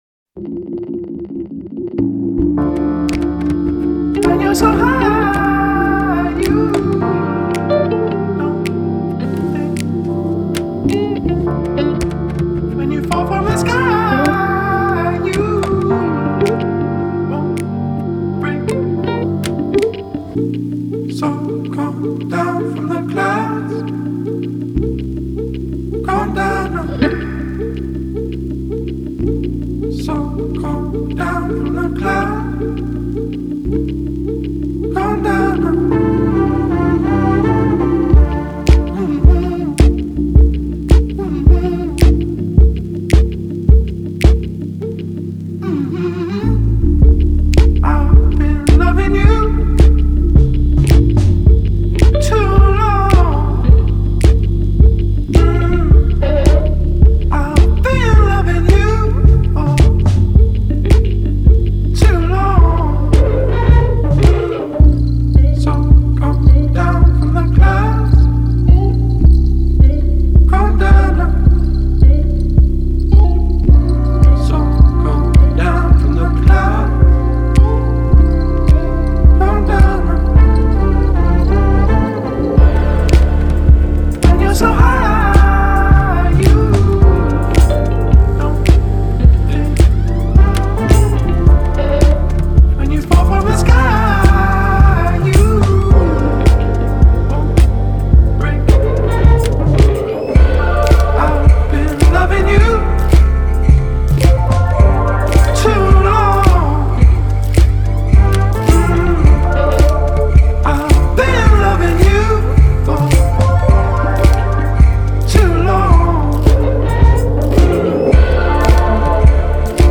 Жанр: Alternative Dance.